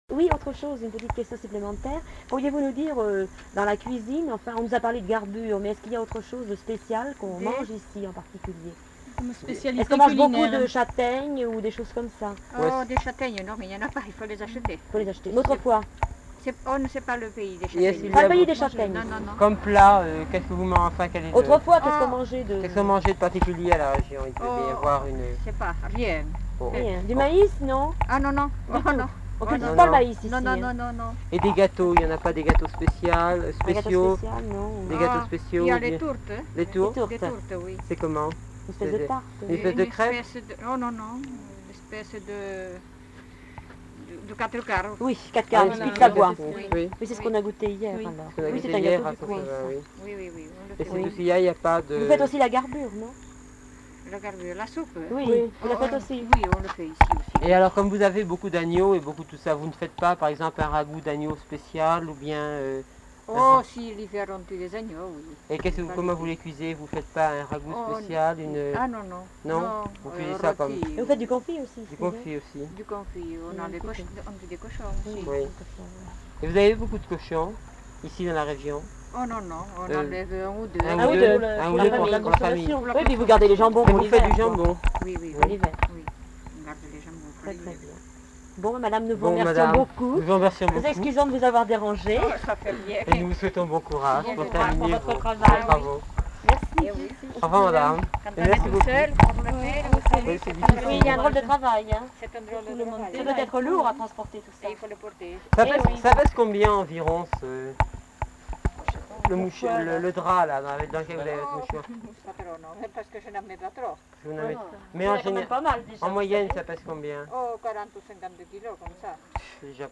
Aire culturelle : Bigorre
Genre : témoignage thématique
Notes consultables : L'informatrice n'est pas identifiée.